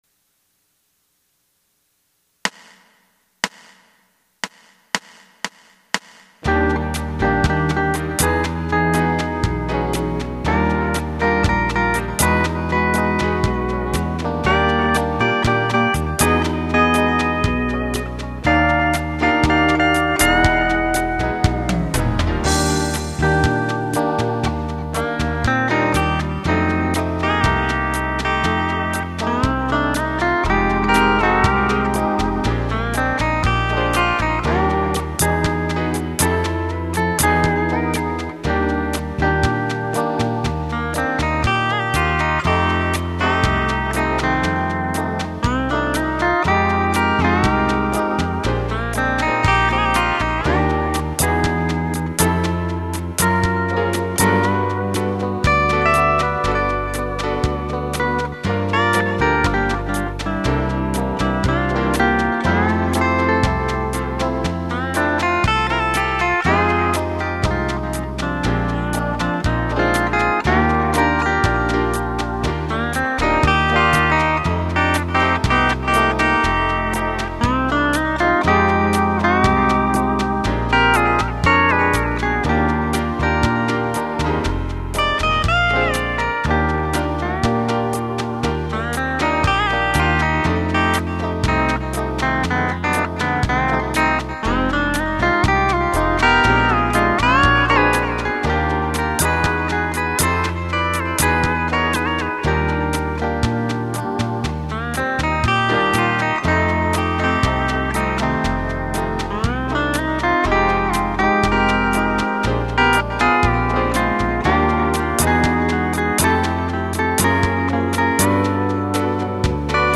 (jazz)